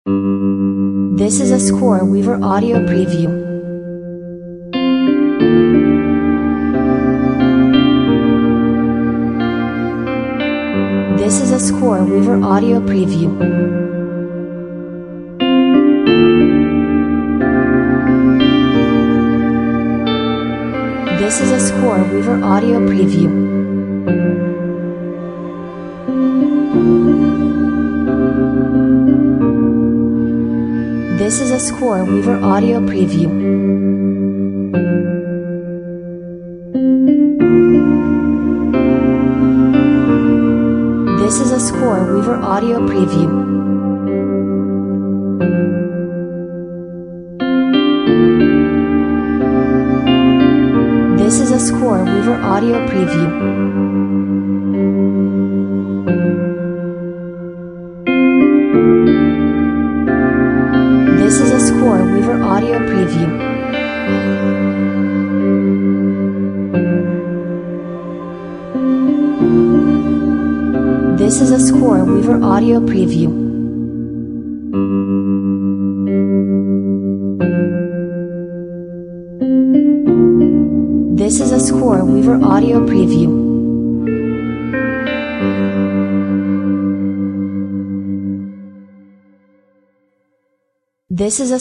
Melancholic post rock ambiance with reverby guitars.